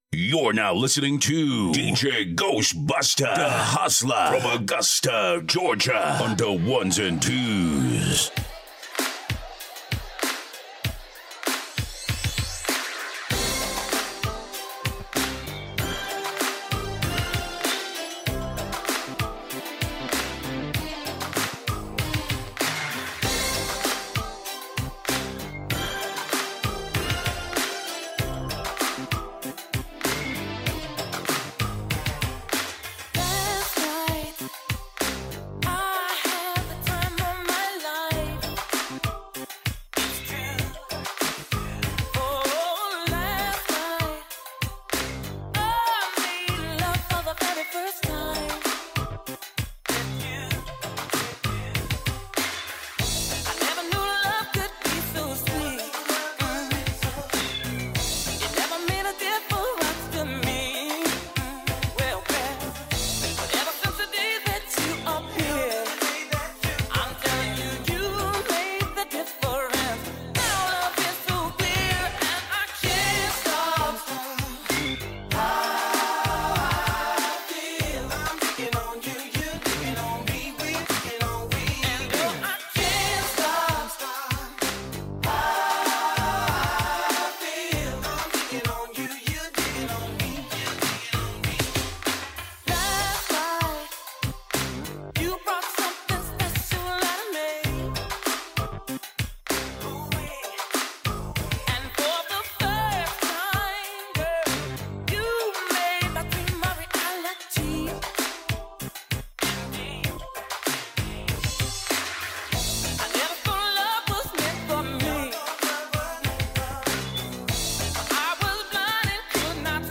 Dance
Quick Smooth Feel Good Mix